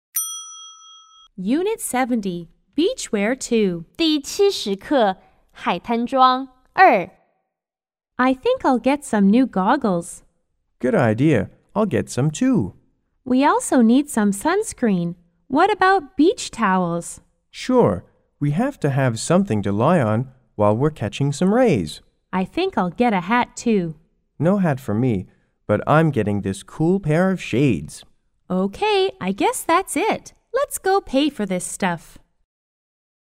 B= Boy G=Girl